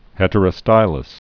(hĕtər-ə-stīləs)